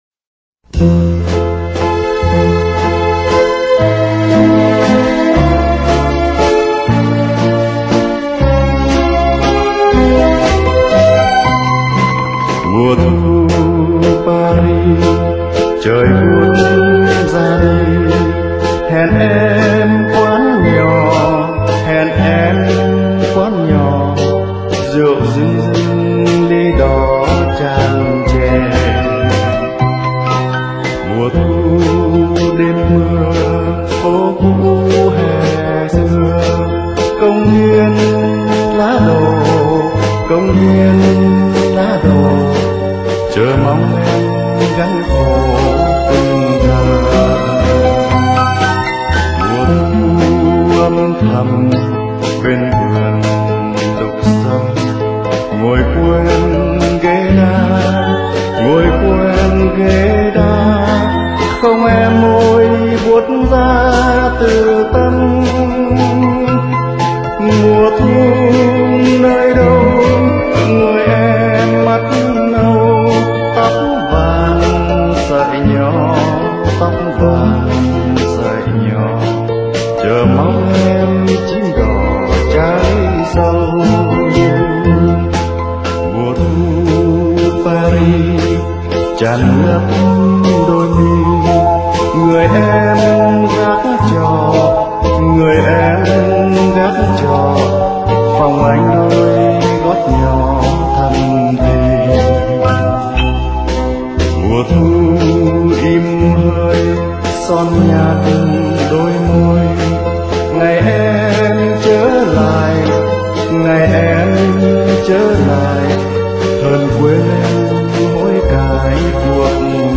rất bay bổng và trữ tình